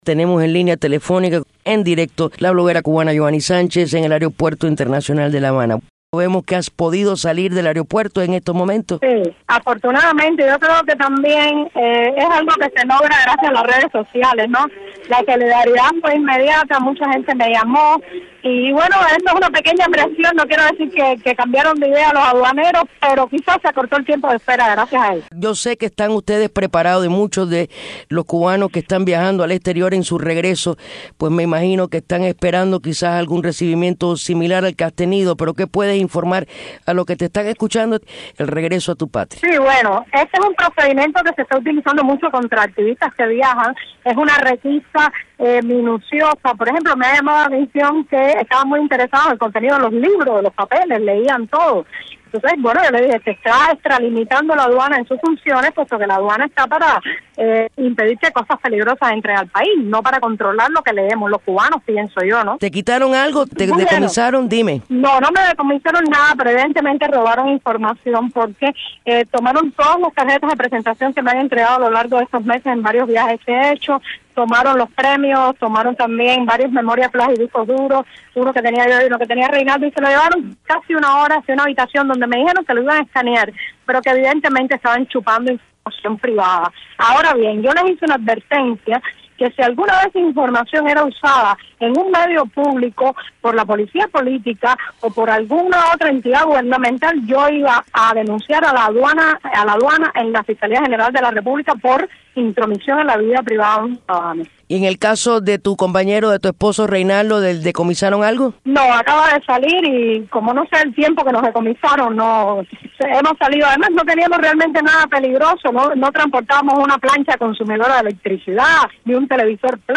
Palabras de Yoani Sánchez al salir del aeropuerto